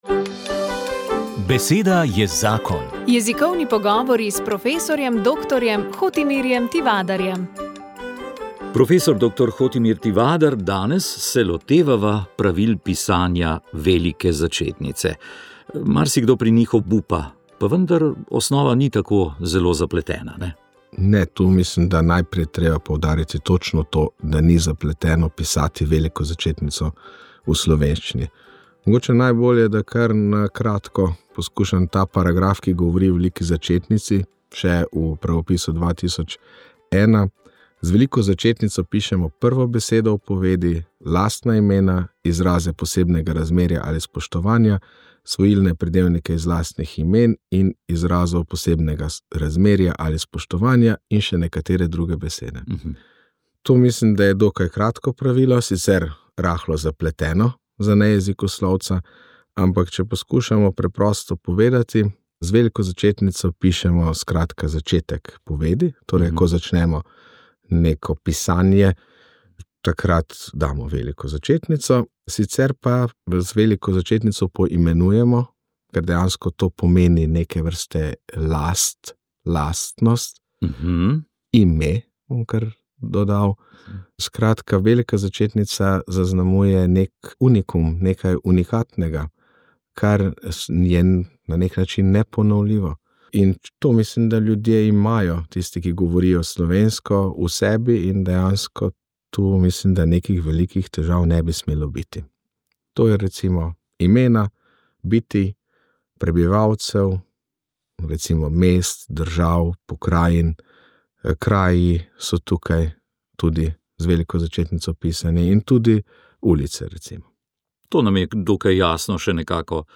Do sedaj, ko lahko pesmi s koncerta slišite tukaj.
intervju